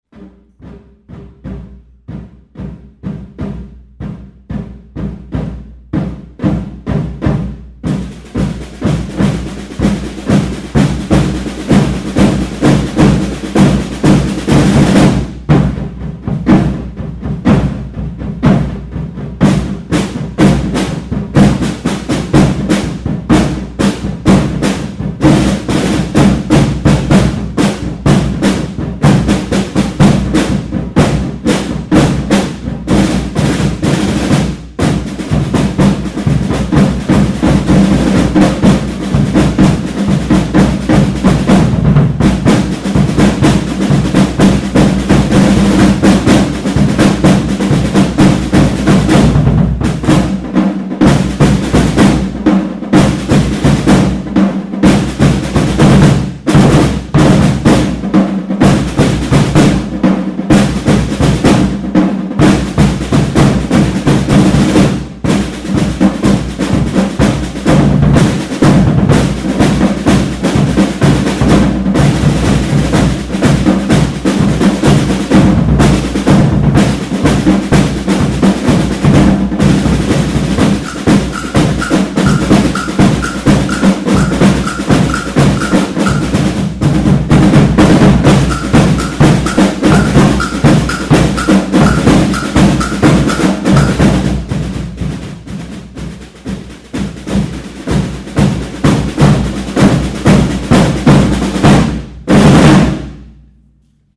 Noten für Junior Percussion Ensemble - stimmlose Percussion.